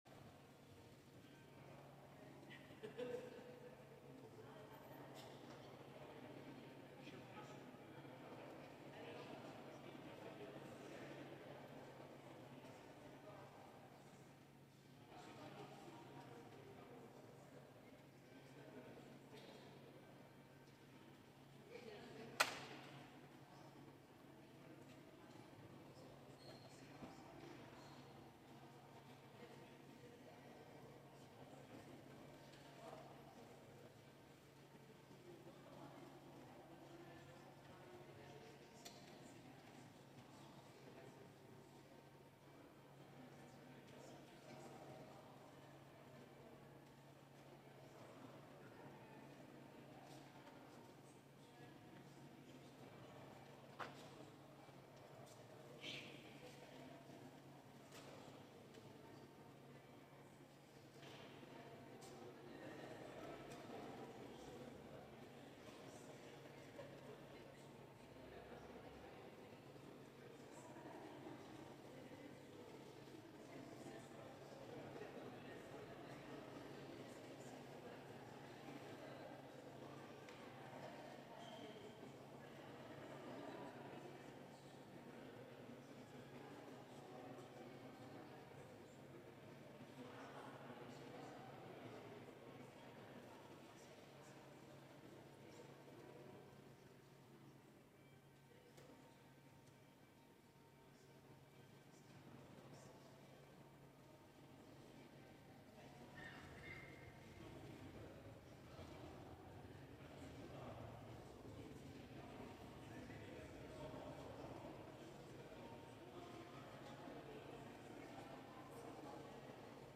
Culte interreligieux du 11 mai 2025 | Oratoire du Louvre
Écouter le culte entier (Télécharger au format MP3)